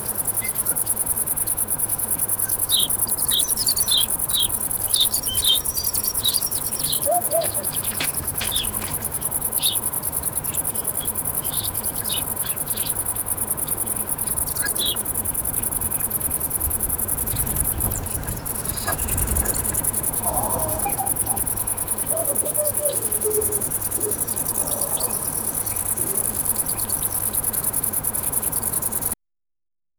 Soundscape of a wide green meadow on a sunny day, gentle wind, grass, cheerful rabbit and squirrel playing, small giggles and laughter, peaceful and natural outdoor atmosphere.
soundscape-of-a-wide-gree-lajfll2p.wav